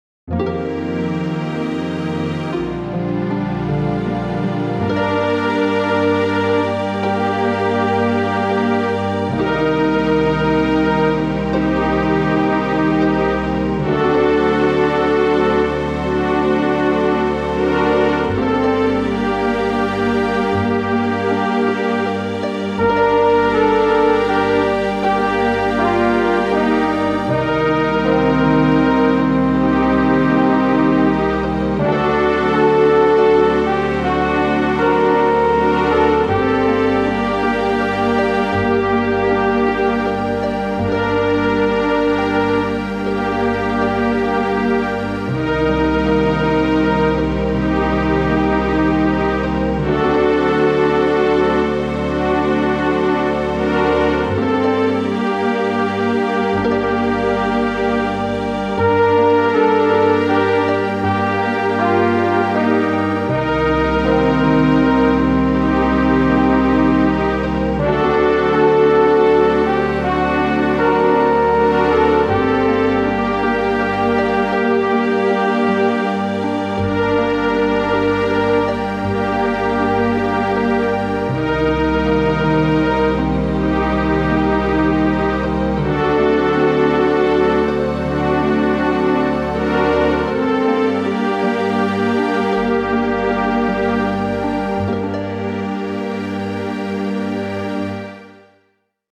We also sang Ruach, an old Hebrew melody according to the music: